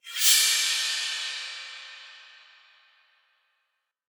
cym B.ogg